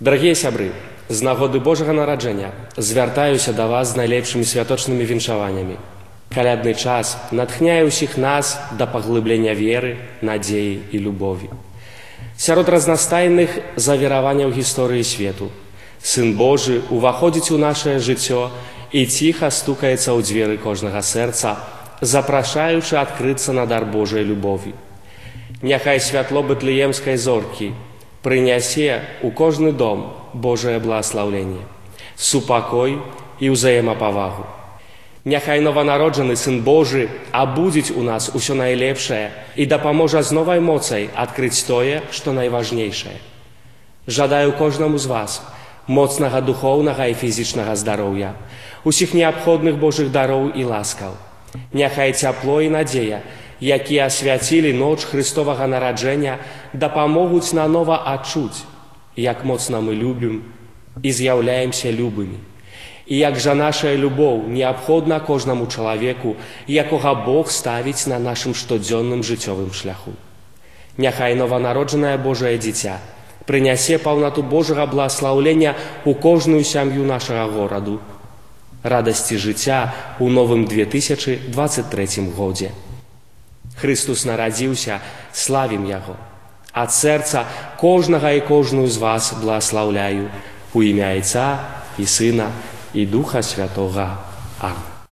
ksyondz-sajt.ogg